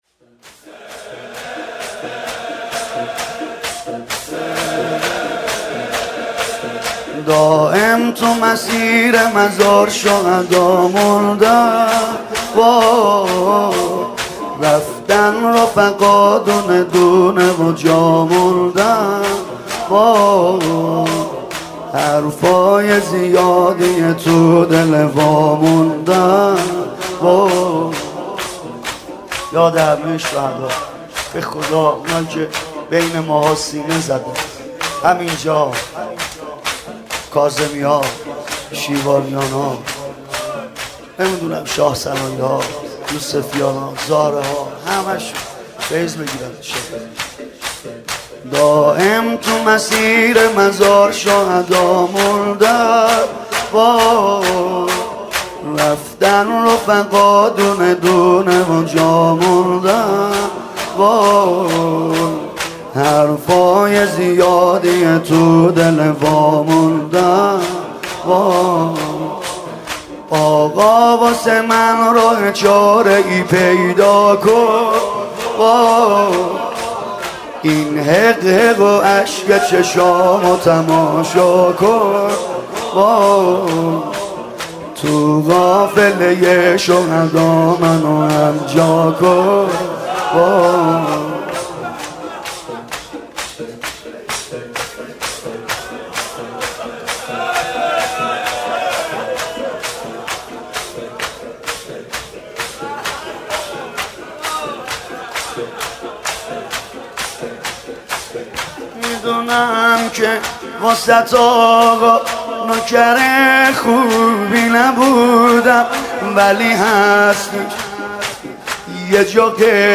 مداحی جدید
شور جدید